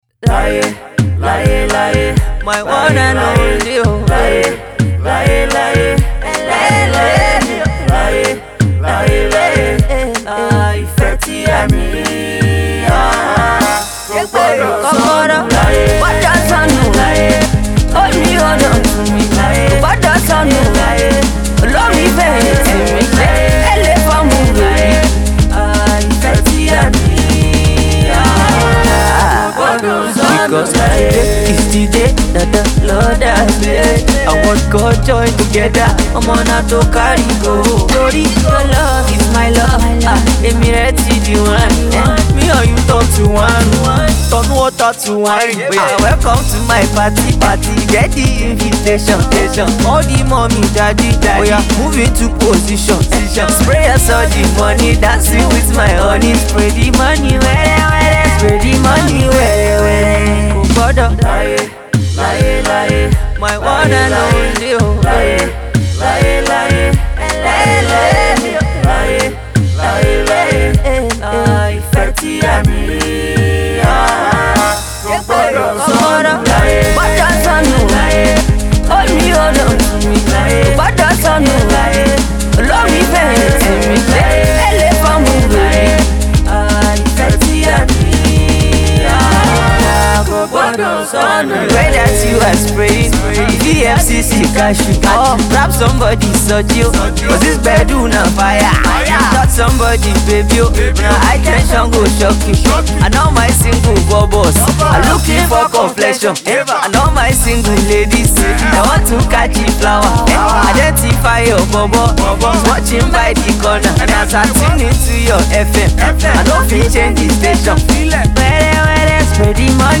crooner
romantic song